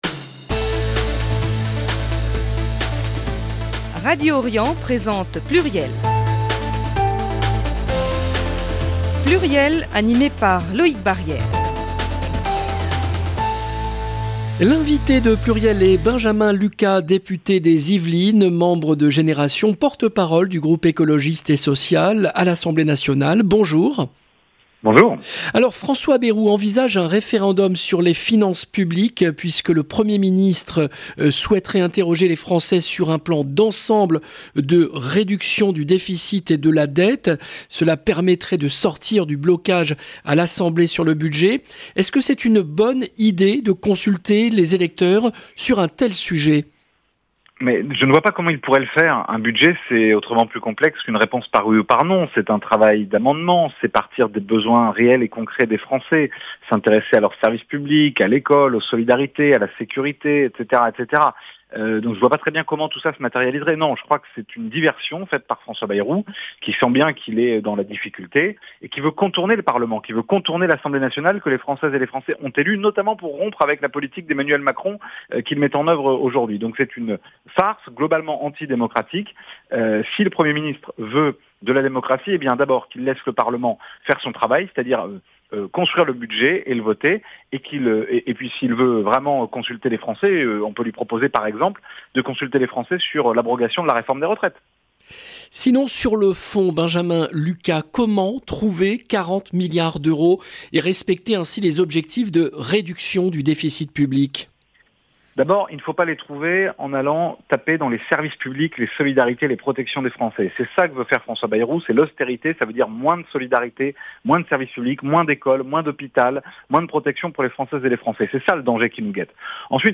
Benjamin Lucas, député des Yvelines